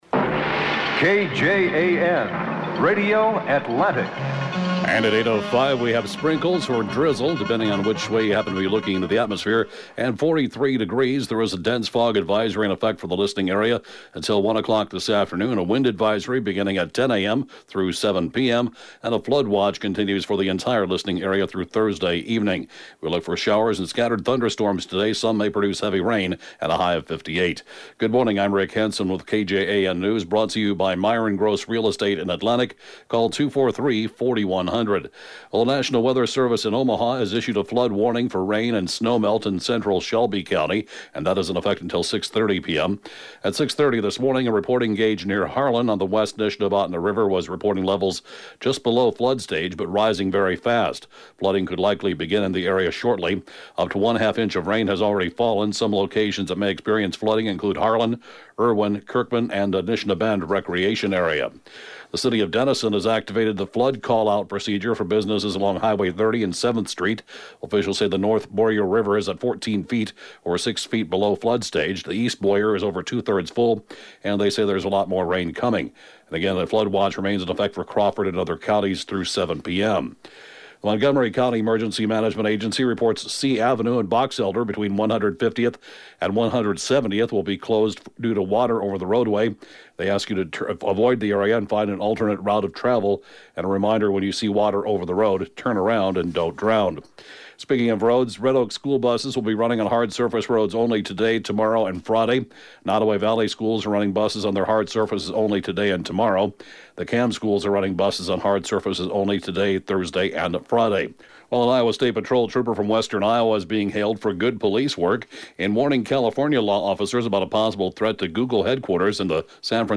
(Podcast) KJAN 8-a.m. News, 3/13/19